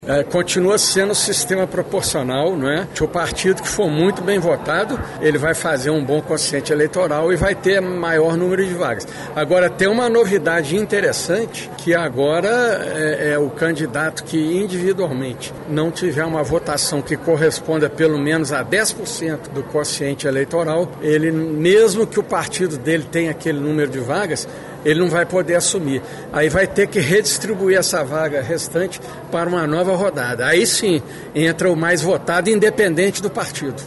Segundo o Presidente do TRE/MG – pela primeira vez por força de uma emenda constitucional de 2017 que só passará a vigorar este ano, não haverá mais coligações nas eleições proporcionais para disputar cargos de vereador. Questionado em coletiva de imprensa se quem mais voto tiver seria eleito – o Presidente do TRE/MG – respondeu.